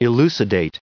856_elucidate.ogg